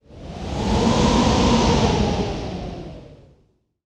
Wind_Howl.mp3